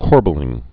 (kôrbə-lĭng, -bĕl-)